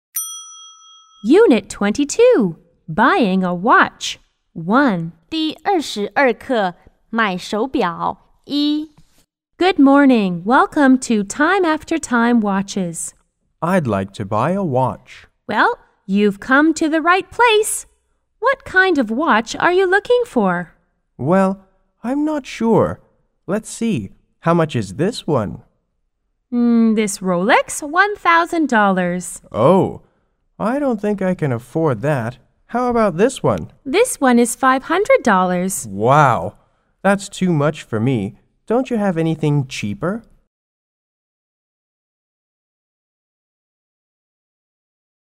S= Salesperson C= Customer